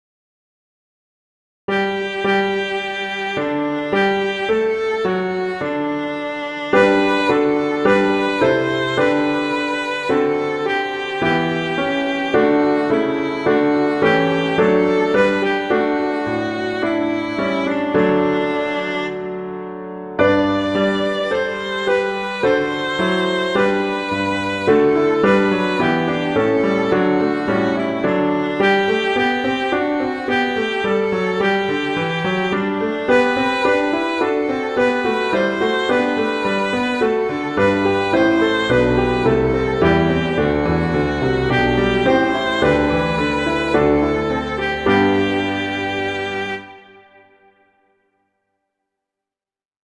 violin and piano